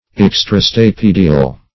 Search Result for " extrastapedial" : The Collaborative International Dictionary of English v.0.48: Extrastapedial \Ex`tra*sta*pe"di*al\ ([e^]ks`tr[.a]*sta[.a]*p[=e]"d[i^]*al), a. (Anat.)
extrastapedial.mp3